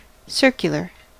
Ääntäminen
IPA : /ˈsɜː(ɹ)k.jə.lə(ɹ)/